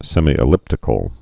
(sĕmē-ĭ-lĭptĭ-kəl, sĕmī-)